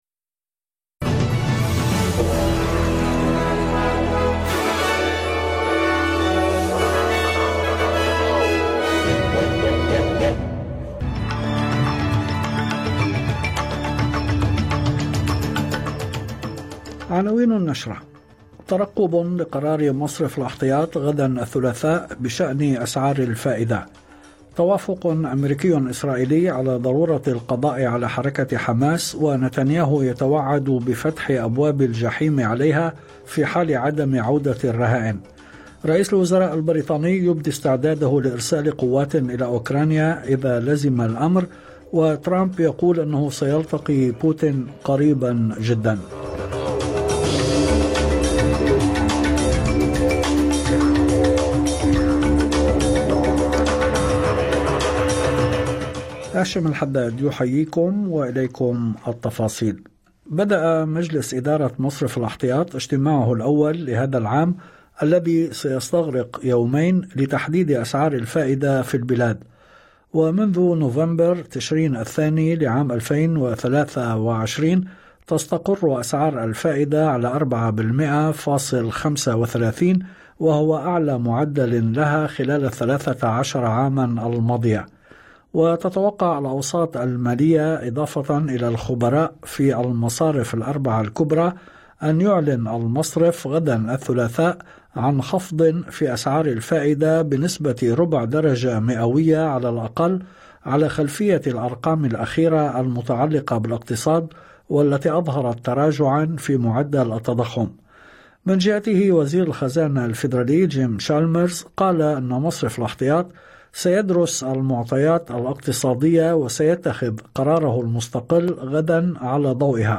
نشرة أخبار المساء 17/2/2025